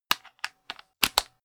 Royalty free sounds: Kitchen